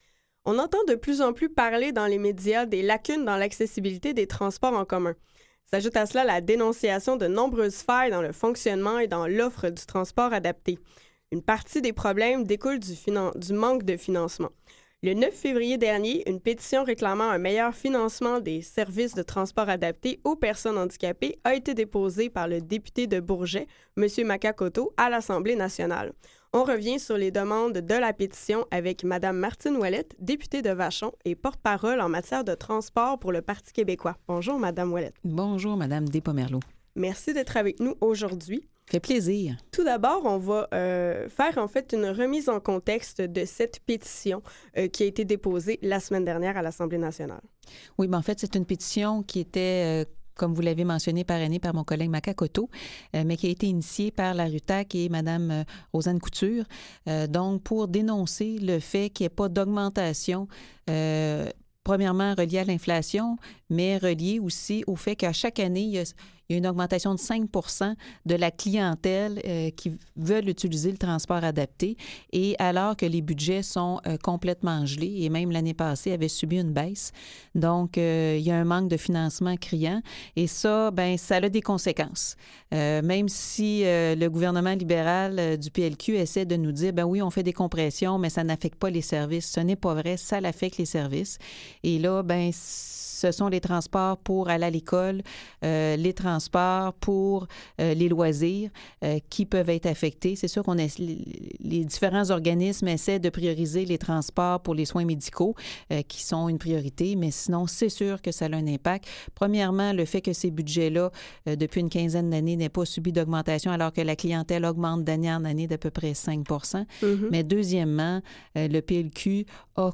Martine Ouellet, députée de VachonMartine Ouellet, députée de Vachon et porte-parole du Parti Québécois en matière de transports pour parler du financement du transport adapté.